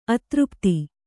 ♪ atřpti